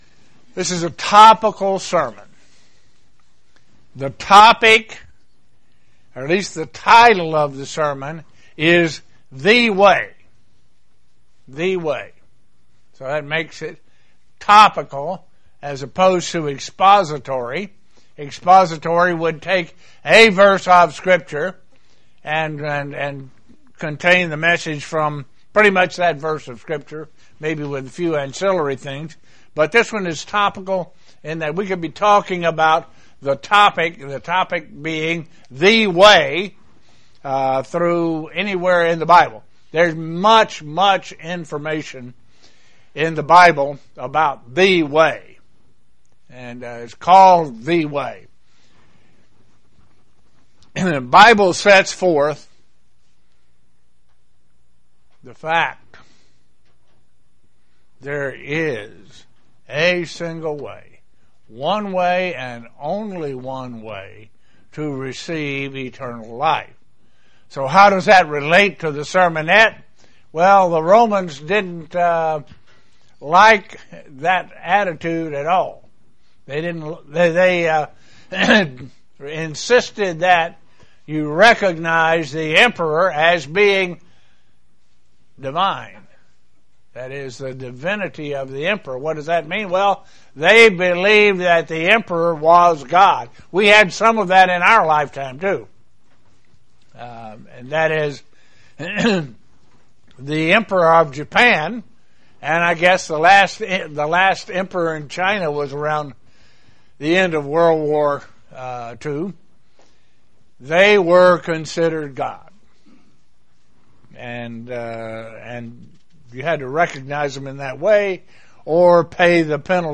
Sermons
Given in Elmira, NY